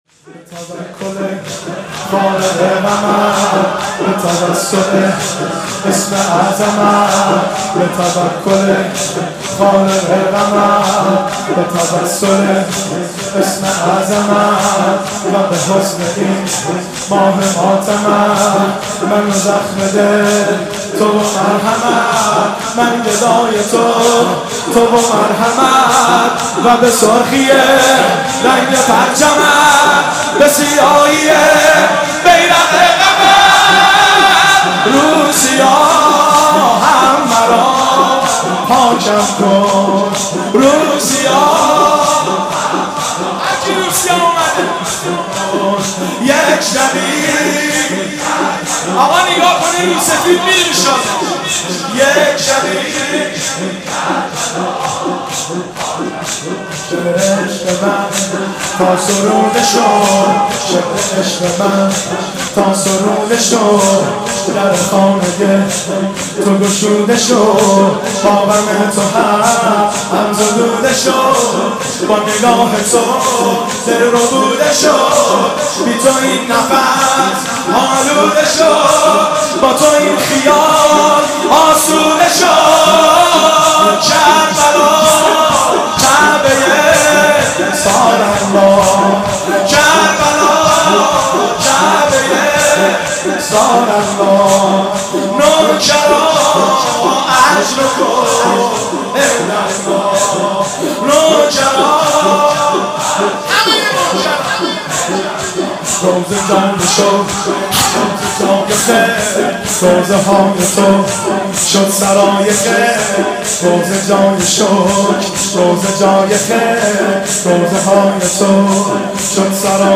شب اول محرم 1392
هیئت خادم الرضا (ع) قم